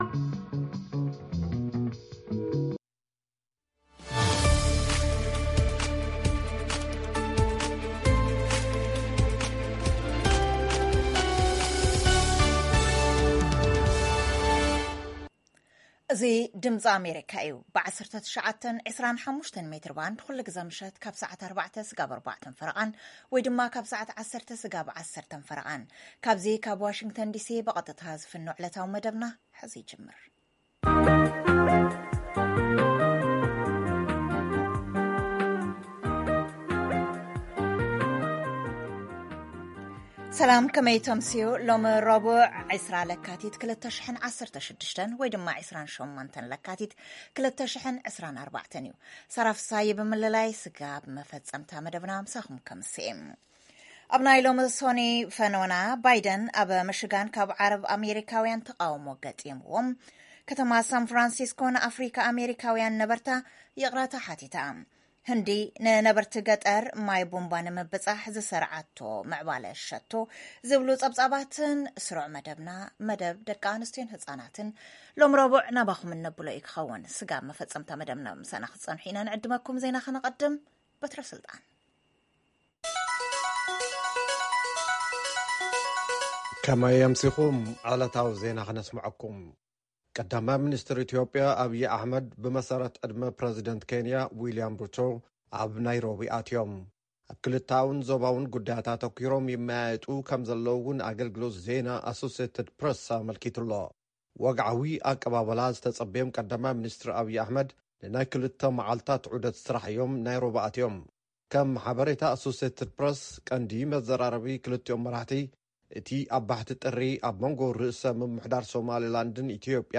ፈነወ ትግርኛ ብናይ`ዚ መዓልቲ ዓበይቲ ዜና ይጅምር ። ካብ ኤርትራን ኢትዮጵያን ዝረኽቦም ቃለ-መጠይቓትን ሰሙናዊ መደባትን ድማ የስዕብ ። ሰሙናዊ መደባት ረቡዕ፡ ህዝቢ ምስ ህዝቢ